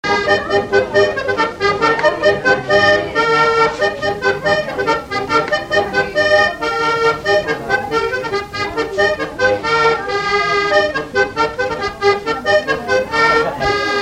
Vendée
Couplets à danser
danse : branle : courante, maraîchine
Pièce musicale inédite